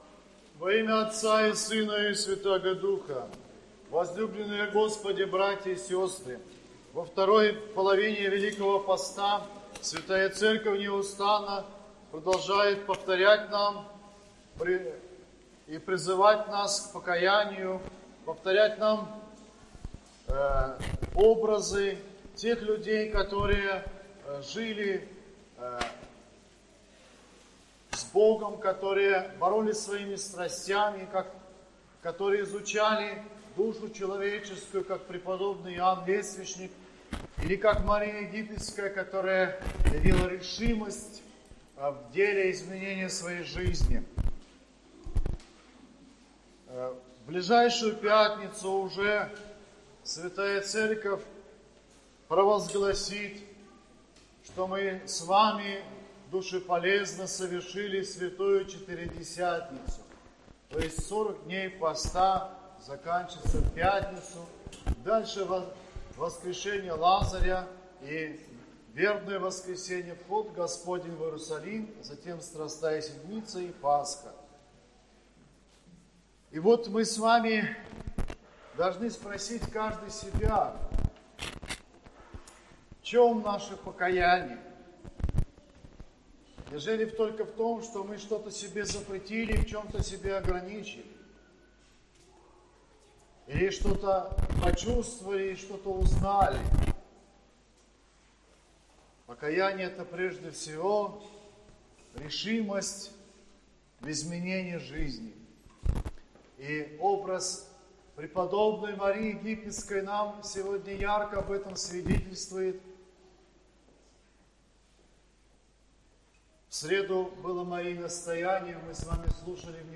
Воскресная проповедь